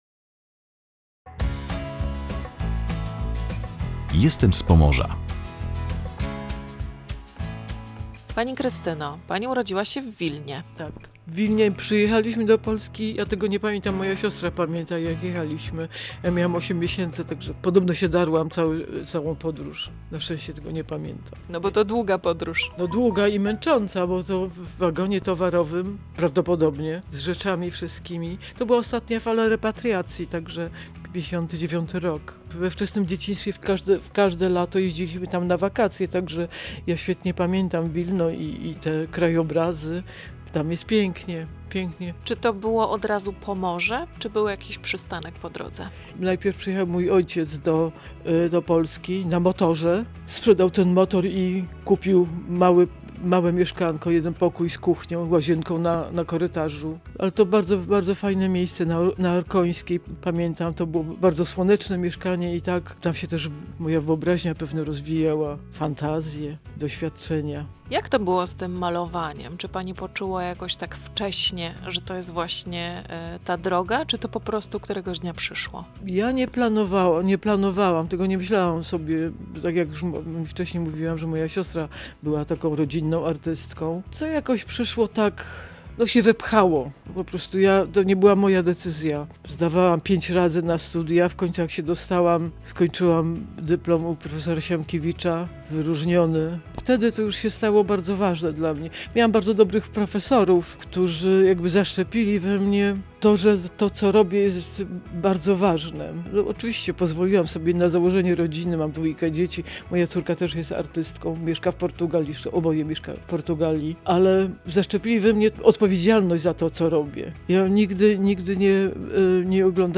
Rozmowa z jedną z najwybitniejszych polskich pejzażystek